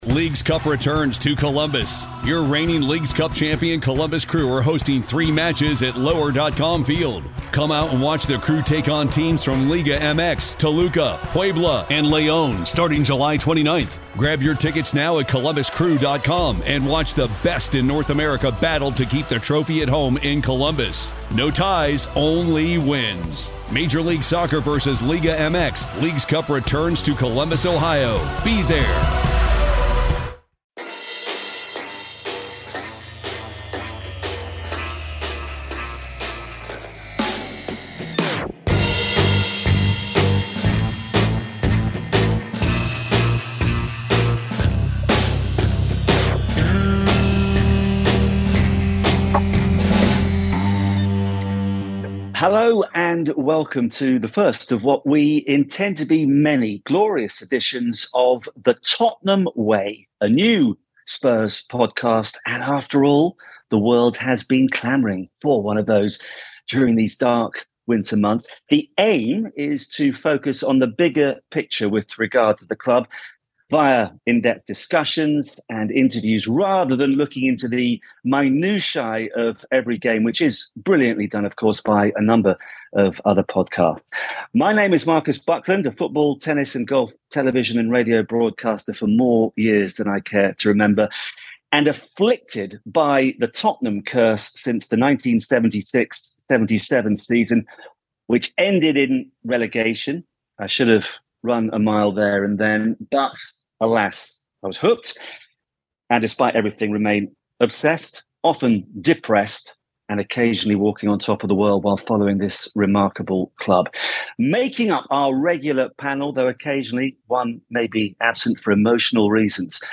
After a brief discussion of this week's big talking points, including Harry Kane's contract, the mounting pressure on Daniel Levy, Fabio Paratici's ban from Italian football and the win over Fulham, the panel holds an in-depth discussion on Lloris. The goalkeeper's form has nosedived since the World Cup but is it the result of an inevitable decline or his agony in Qatar?